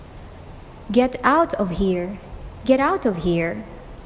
just⌒a                    justa
get⌒out                 gerout
wait⌒a                   waita